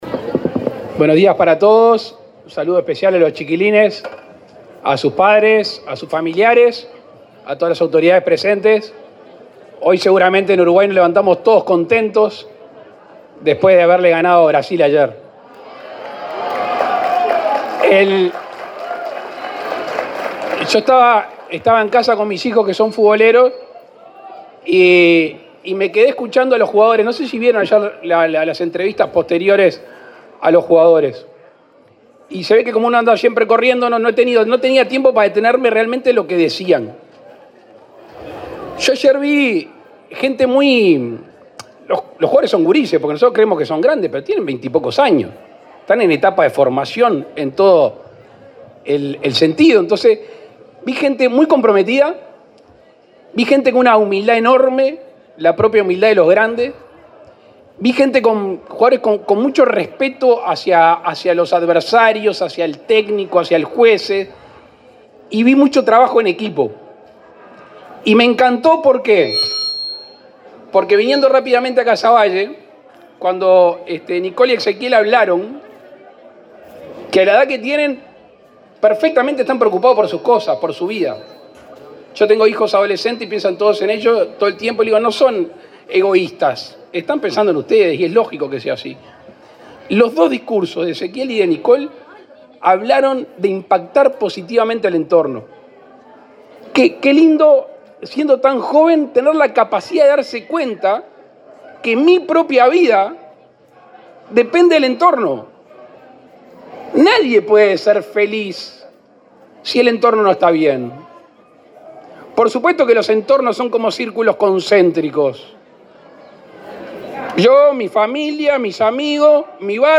Palabras del presidente Luis Lacalle Pou
El presidente de la República, Luis Lacalle Pou, encabezó, este miércoles 18, en el barrio Casavalle de Montevideo, la ceremonia de inauguración del